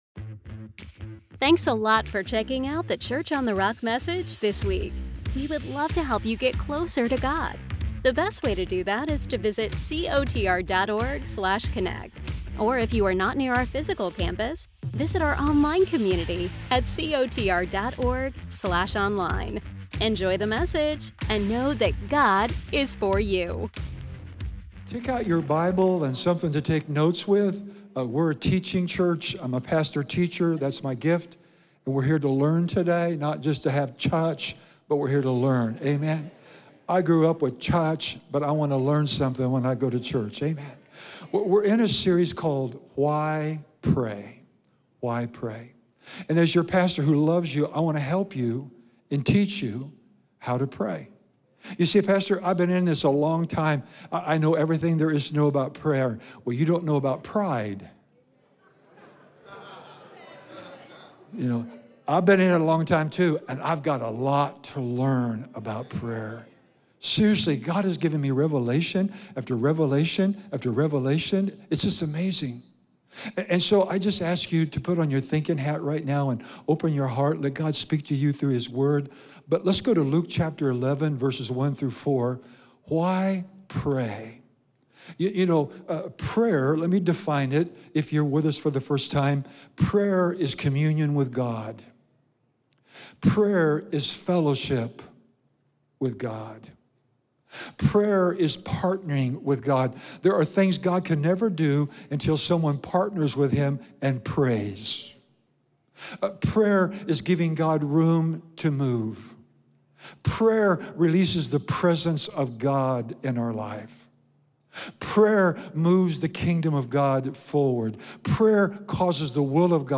Why pray? In Week 3 of our Why Pray? series, Pastor teaches us that prayer is more than words, it’s walking with God, listening before we speak, and partnering with Him to see His will done on earth as it is in heaven. Discover how prayer changes us before it changes our circumstances, why building a personal altar is vital for every believer, and how prayer invites God’s presence, peace, and power into our lives.